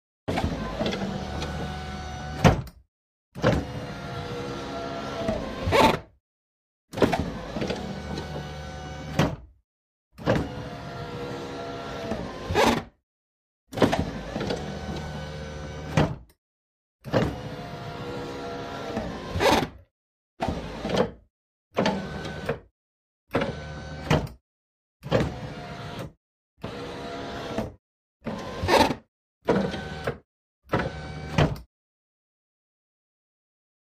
Car Electric Window; Up / Down; Servo Motors And Switch Movement. Movement From Completely Open To Completely Closed. Glass Squeaks Against Rubber With Close.